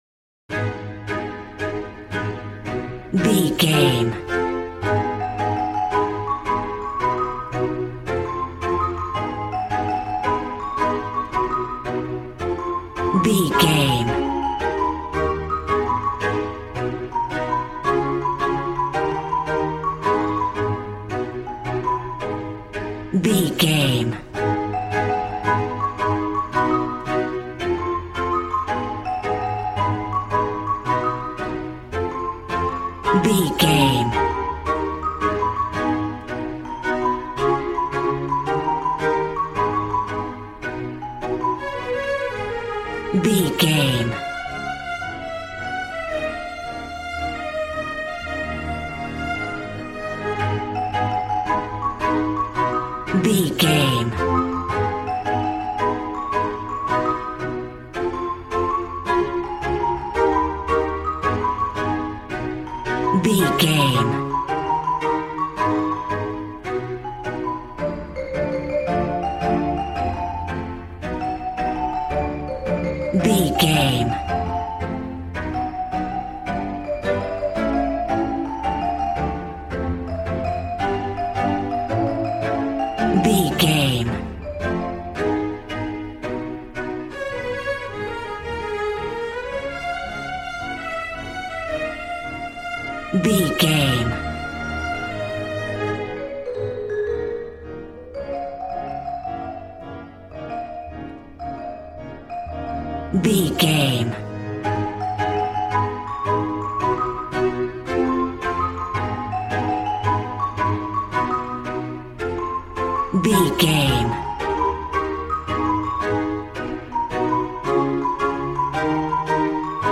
Aeolian/Minor
A♭
cheerful/happy
joyful
drums
acoustic guitar